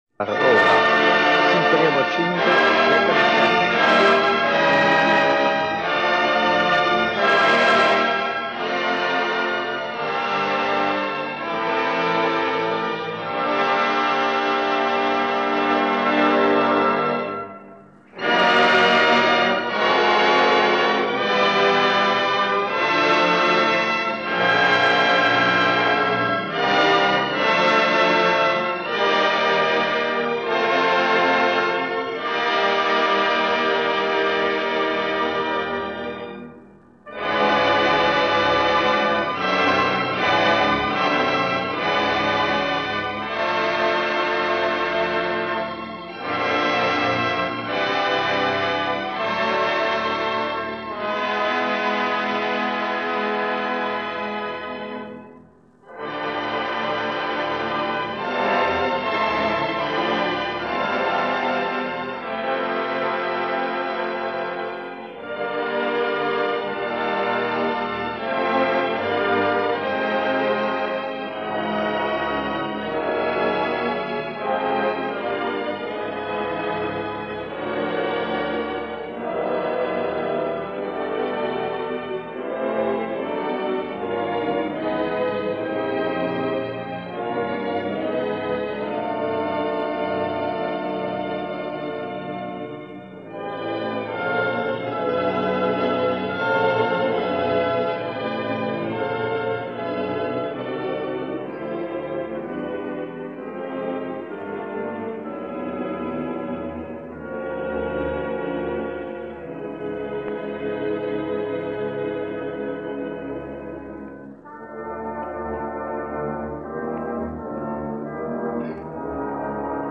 Charles Munch, cond. The French National Orchestra – Honegger: Symphony Number 5 (World Premier)
One reason, I can imagine, is the chatty announcer who manages to keep on talking right over the introduction and into the first few seconds of the piece.